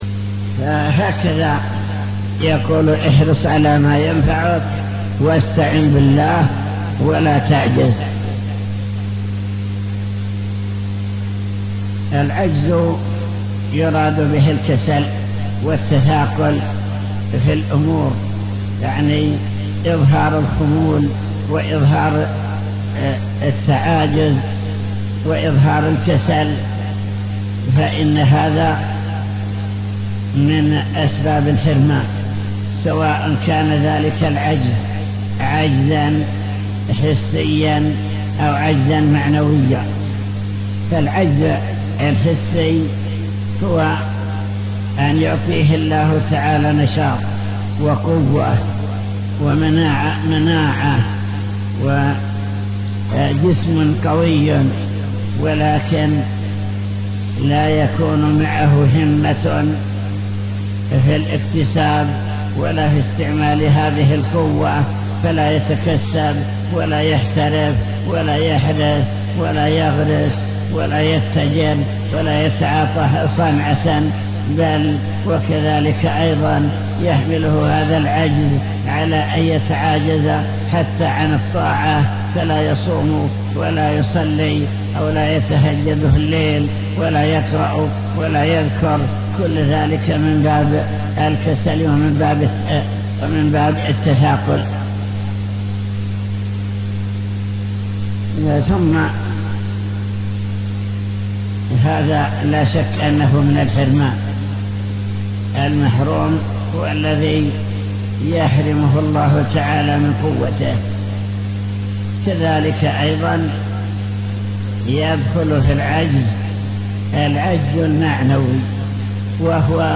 المكتبة الصوتية  تسجيلات - كتب  شرح كتاب بهجة قلوب الأبرار لابن السعدي شرح حديث المؤمن القوي